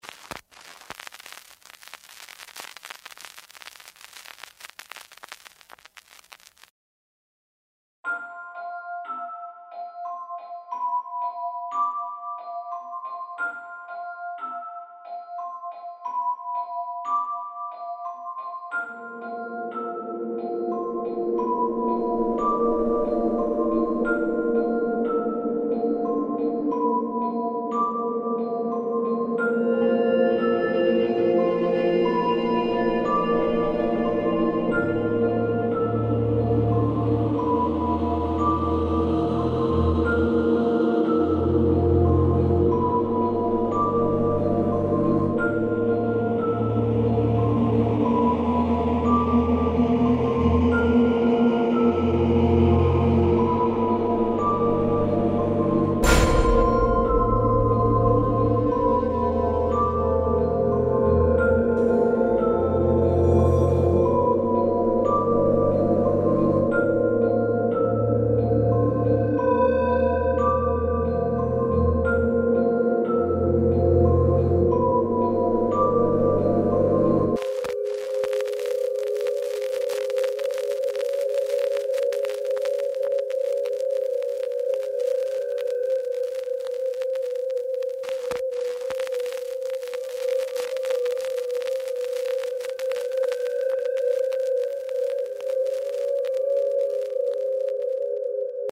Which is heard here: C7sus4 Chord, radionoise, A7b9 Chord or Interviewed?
radionoise